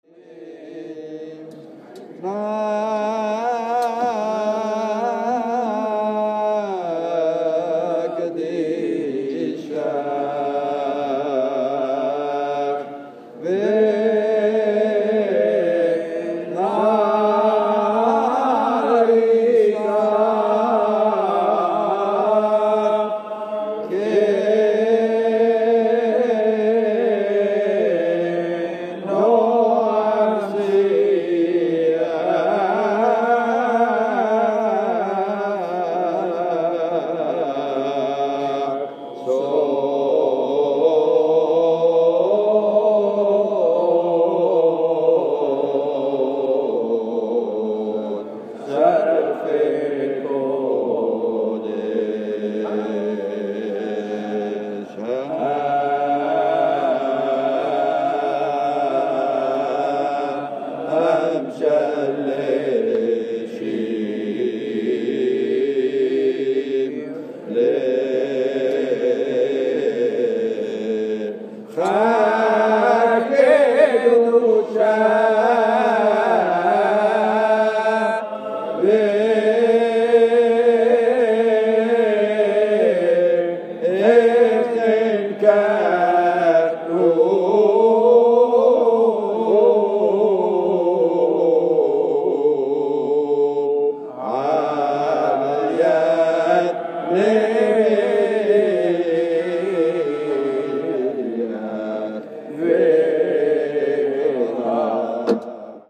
Maqam Hijaz